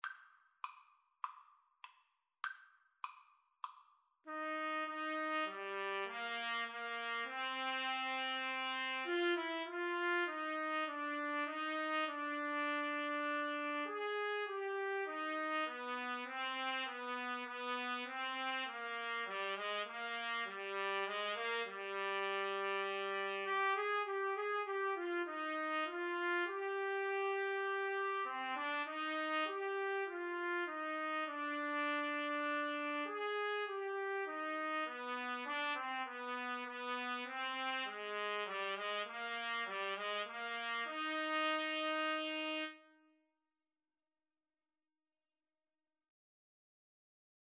4/4 (View more 4/4 Music)
Classical (View more Classical Trumpet Duet Music)